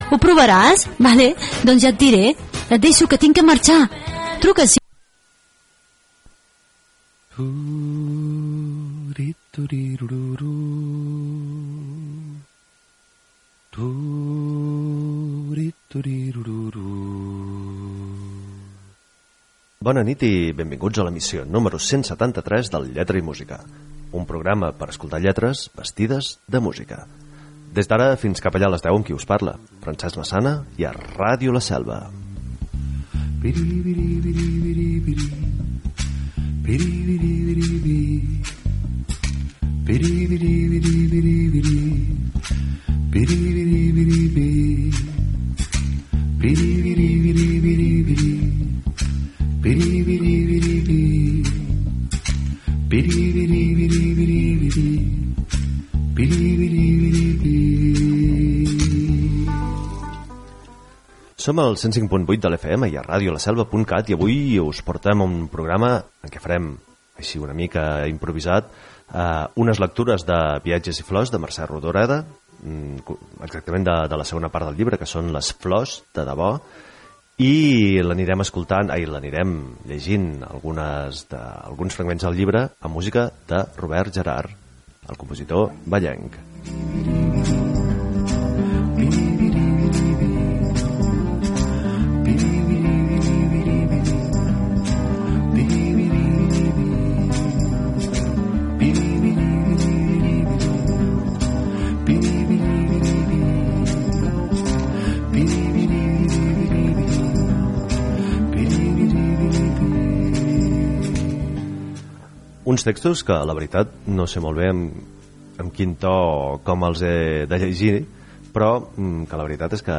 Un programa per escoltar lletres vestides de música. I per llegir textos nus. I per deixar-nos tapar amb músiques sense lletra.